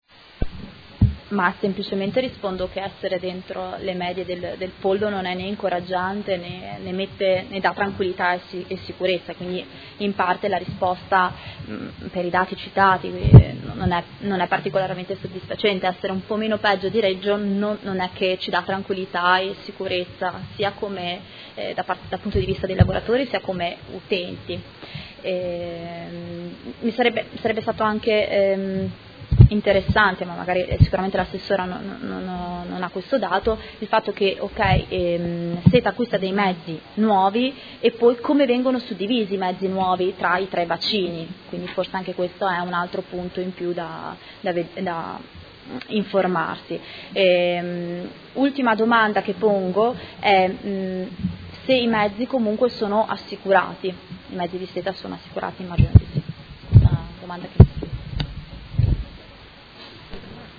Seduta del 21/06/2018 Replica a risposta Assessora. Interrogazione del Gruppo M5S avente per oggetto: Sicurezza mezzi SETA